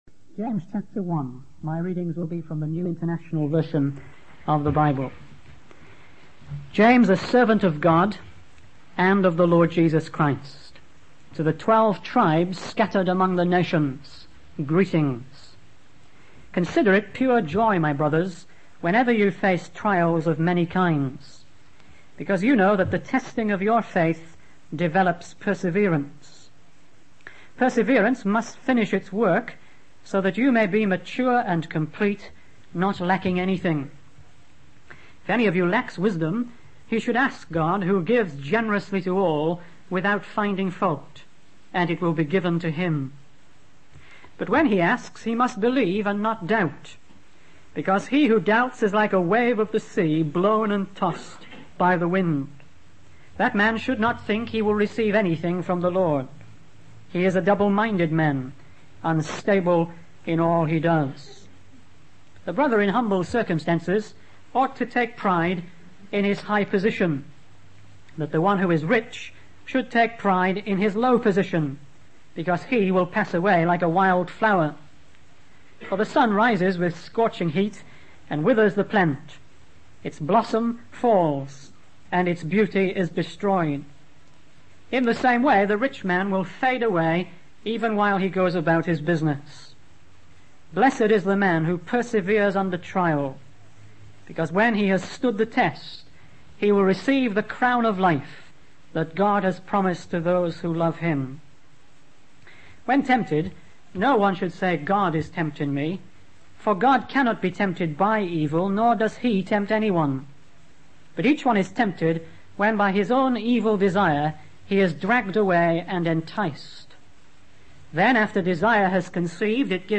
In this sermon, the preacher focuses on the book of James and discusses the importance of understanding God's design in times of trial. He emphasizes that wealth is transient and can easily fade away, just like the grass or flowers.